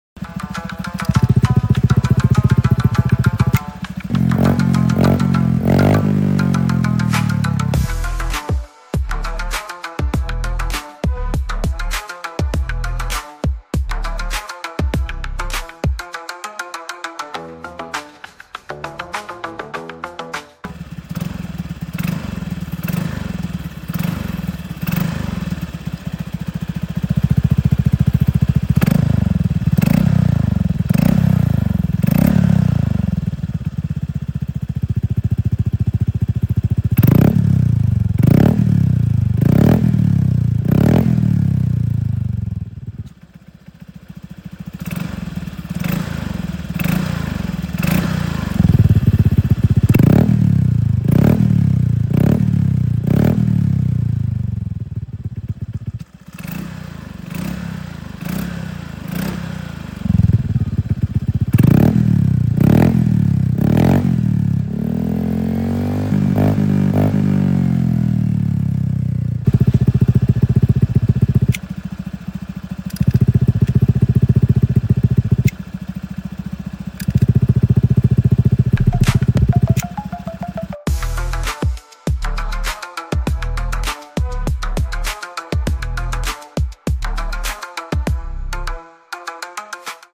Honda Pcx150 Ronin Musashi Sound Effects Free Download